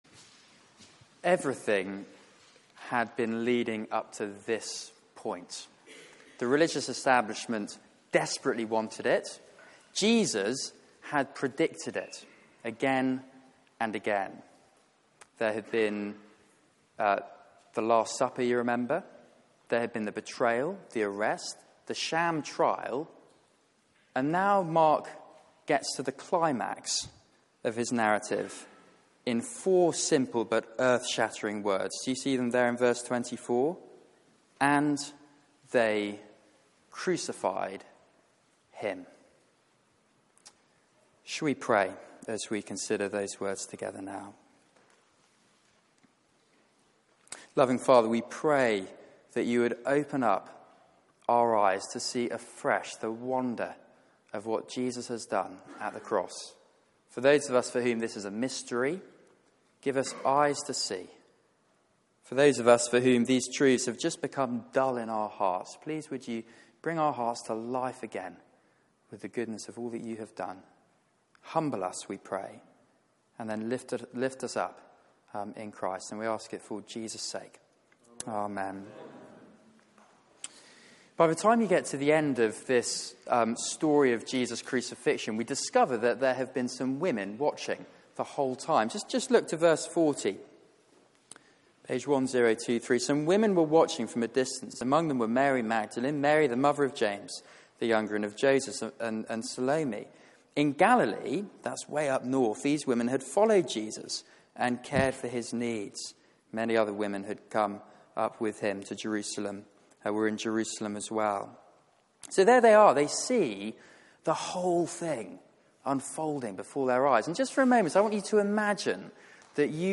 Media for 6:30pm Service on Sun 18th Mar 2018 18:30 Speaker
Theme: King crucified Sermon